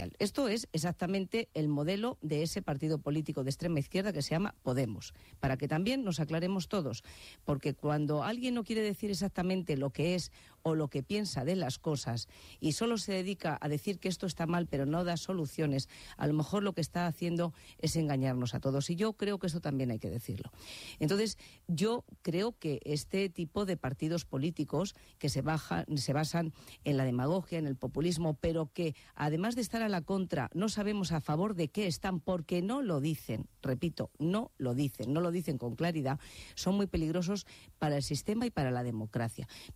CASTILLA LA MANCHA Hoy, en una entrevista en Onda Cero Ampliar Cospedal entrevista en Onda Cero.